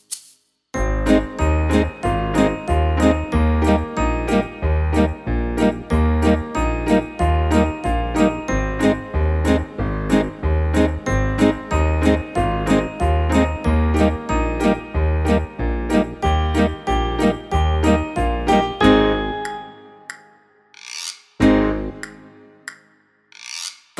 utwór w wersji wokalnej i instrumentalnej